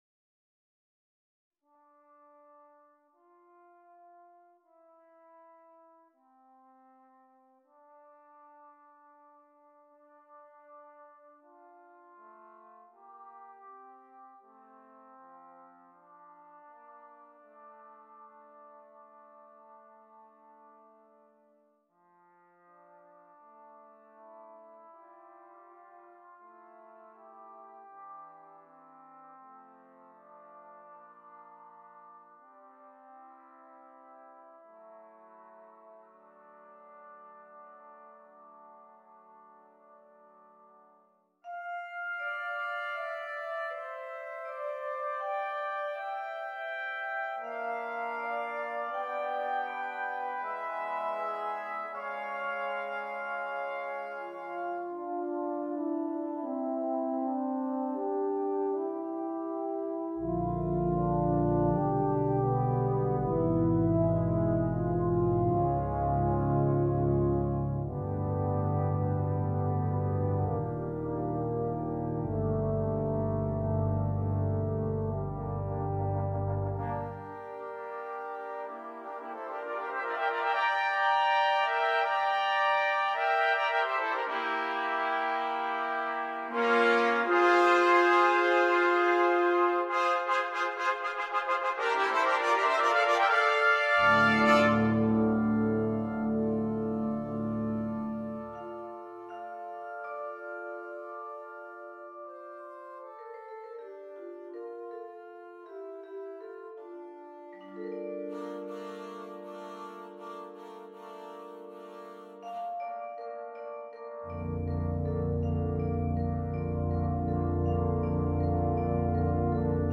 Sheet Music for Brass Band